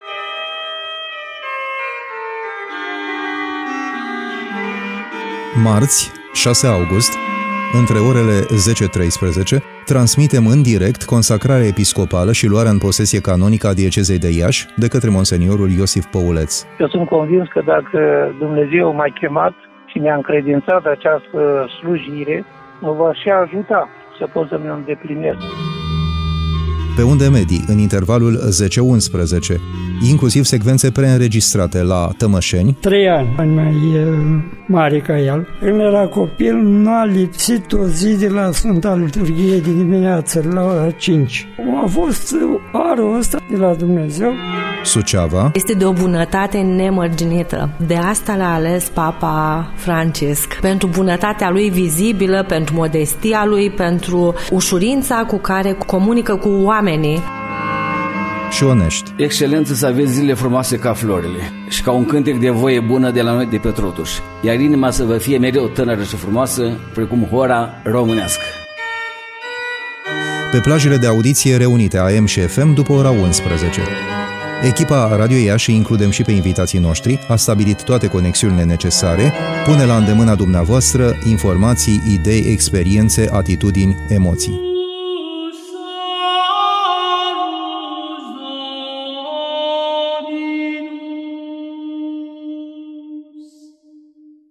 teaser